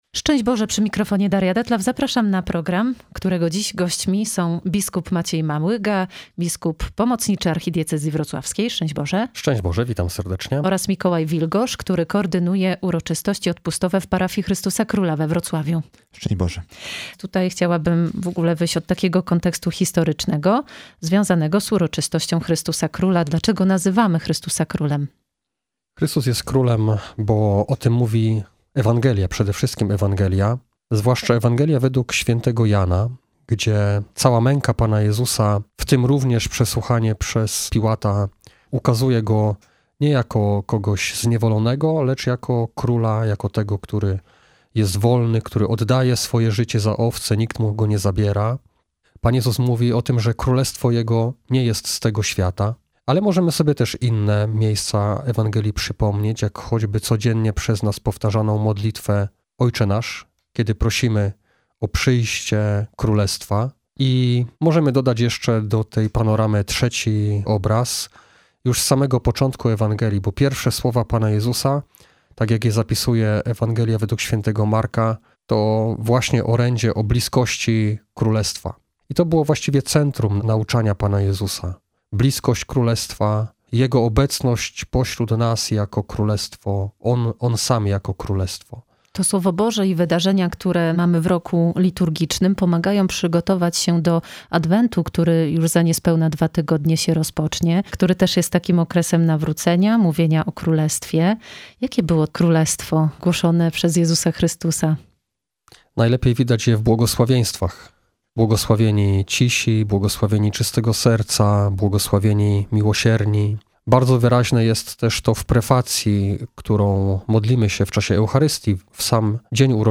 W naszym studiu